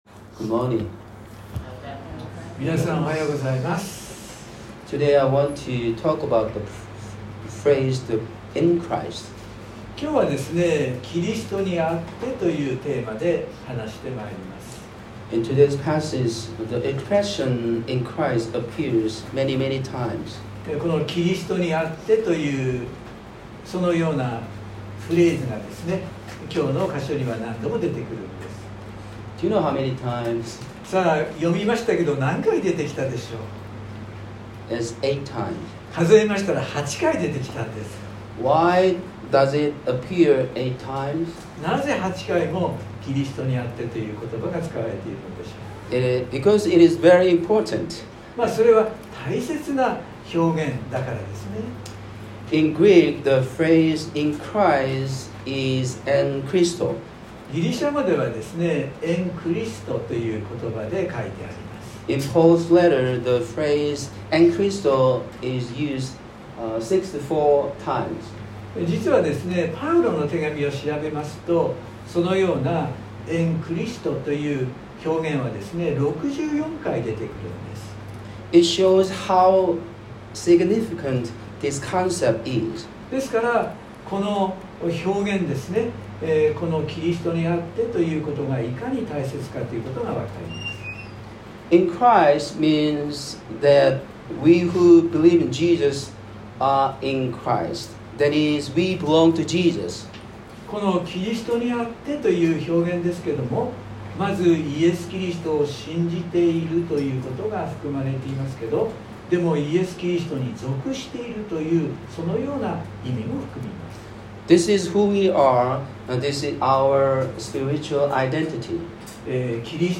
↓メッセージが聞けます。（日曜礼拝録音）【iPhoneで聞けない方はiOSのアップデートをして下さい】原稿は英語のみになります。